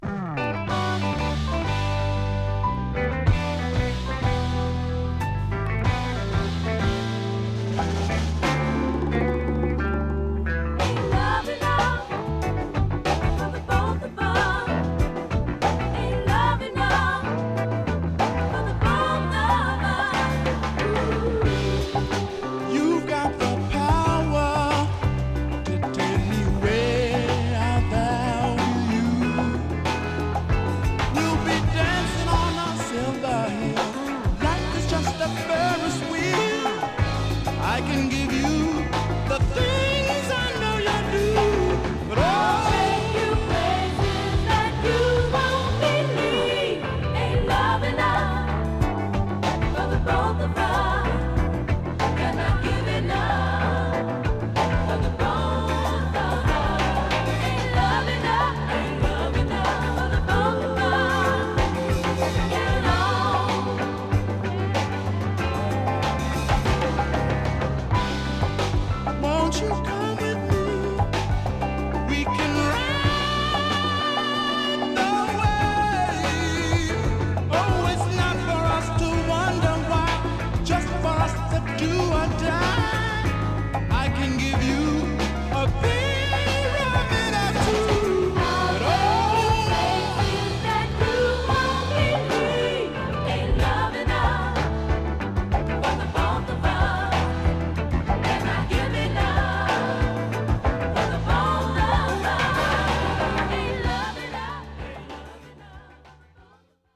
A:Stereo B:Mono仕様